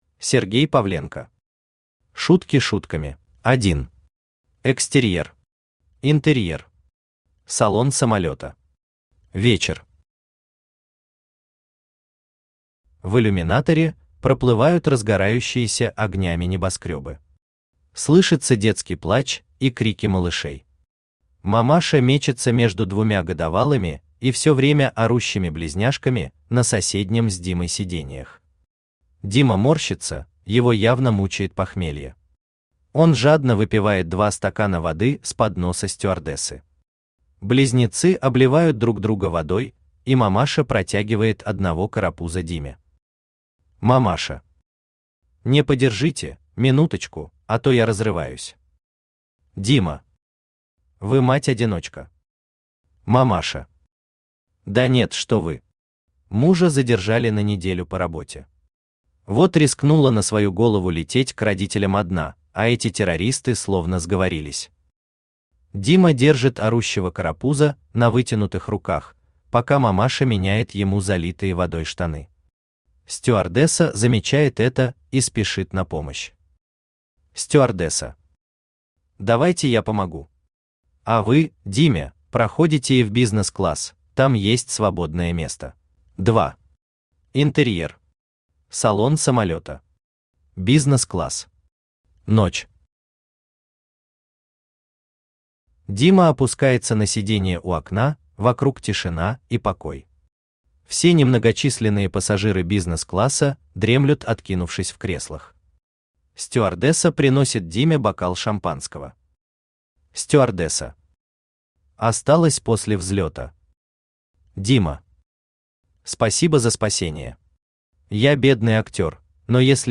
Автор Сергей Анатольевич Павленко Читает аудиокнигу Авточтец ЛитРес.